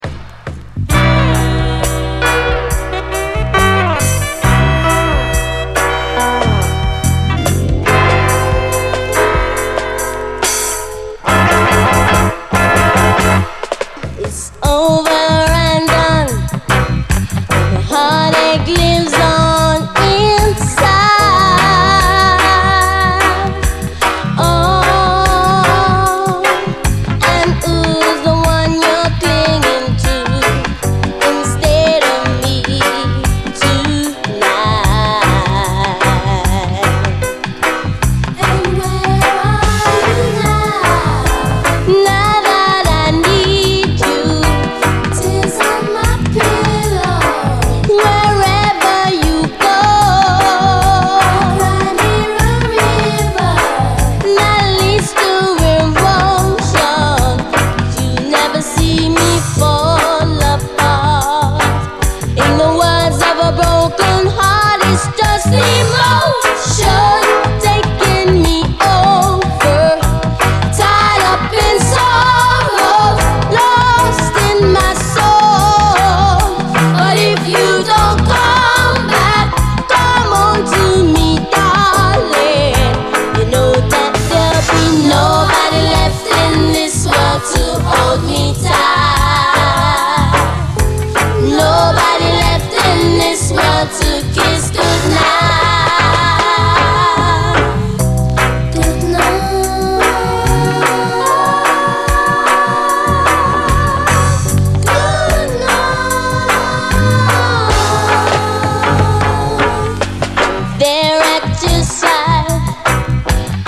REGGAE
後半でダブに接続。